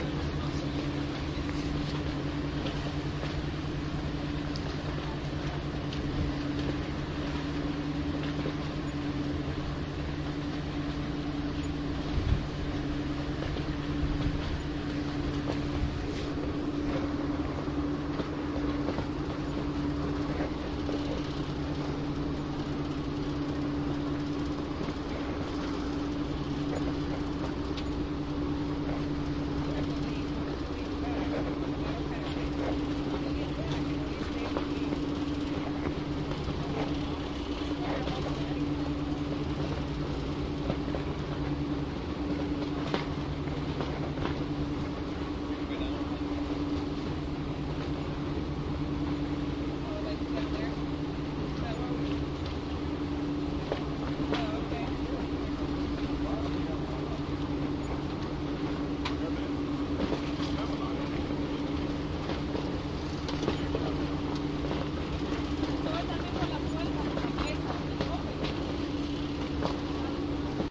公路之旅5 " 卡佩梅渡轮上层甲板
描述：梅角Lewes渡轮的上层甲板用DS40录制，并在Wavosaur中编辑。
Tag: 斗篷可 - 刘易斯 - 轮渡 特拉华州 现场记录 新球衣 海洋